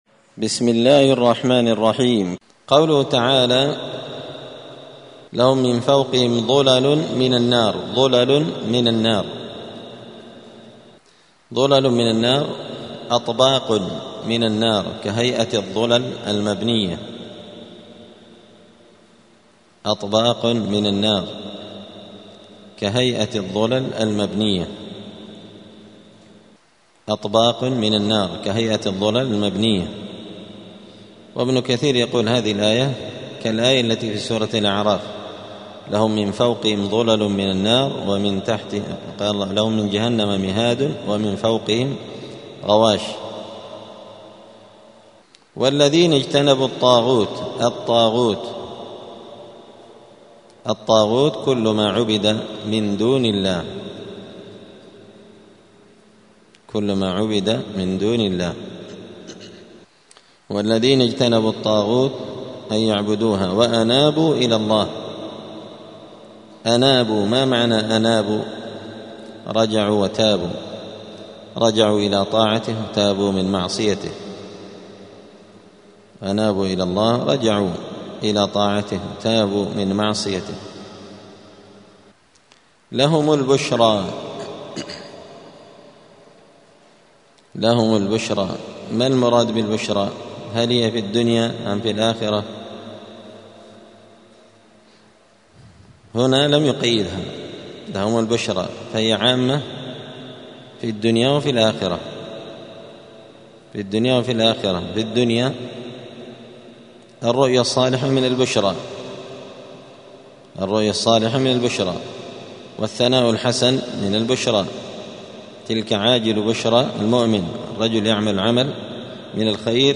*(جزء الزمر سورة الزمر الدرس 255)*